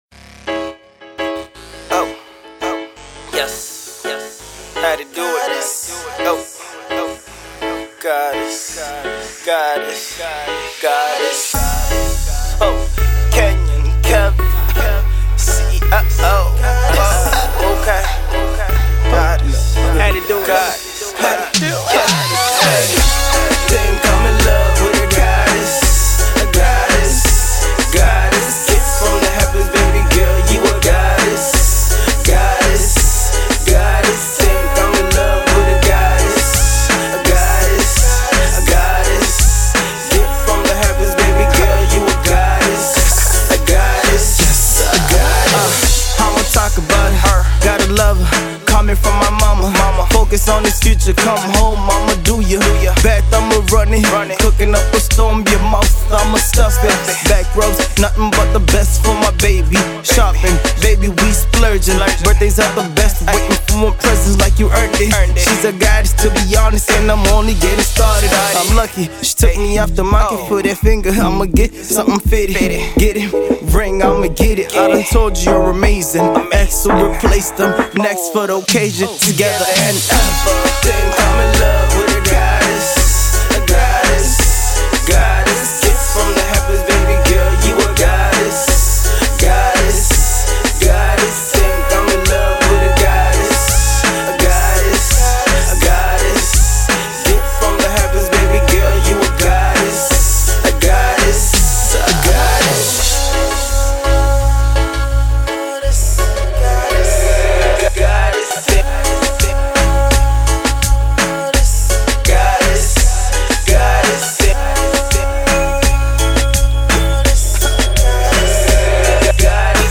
he goes hard